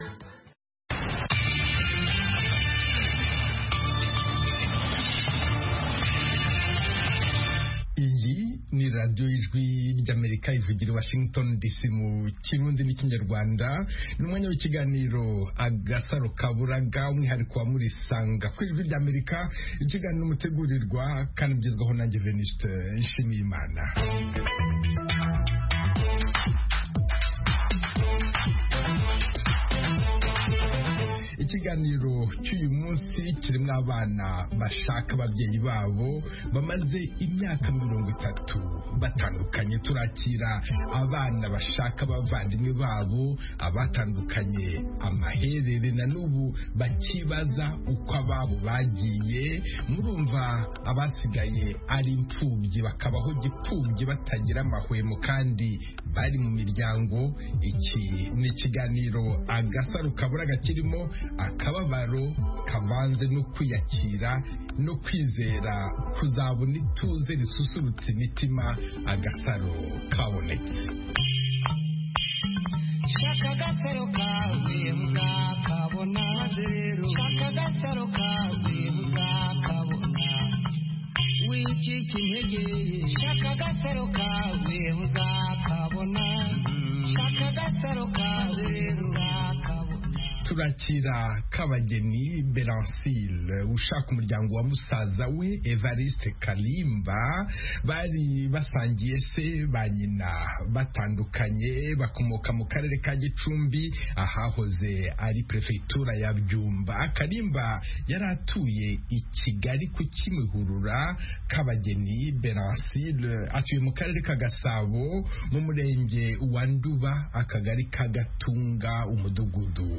Murisanga (1400-1500 UTC): Murisanga itumira umutumirwa, cyangwa abatumirwa kugirango baganire n'abakunzi ba Radiyo Ijwi ry'Amerika. Aha duha ijambo abantu bifuza kuganira n'abatumirwa bacu, batanga ibisobanuro ku bibazo binyuranye bireba ubuzima bw'abantu.